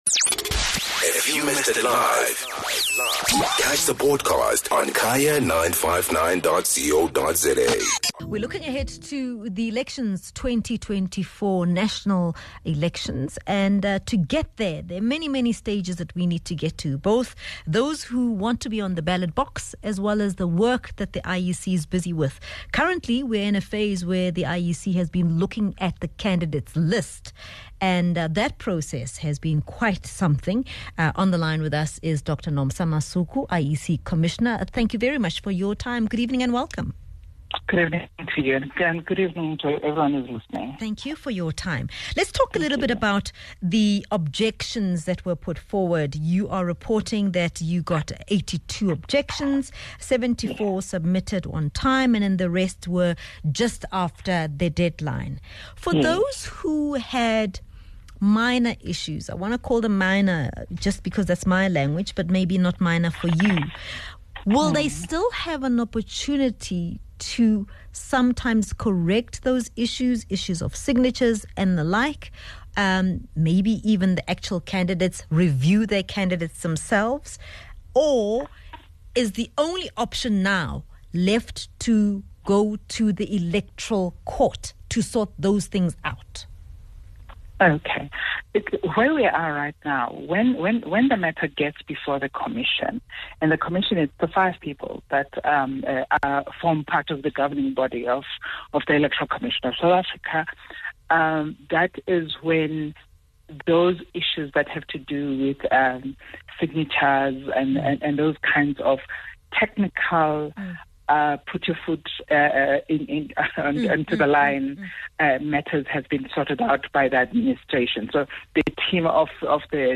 IEC Commissioner, Dr Nomsa Masuku helps us understand the reasons behind the removal of a political party from the voting ballot paper.